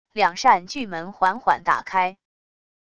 两扇巨门缓缓打开wav音频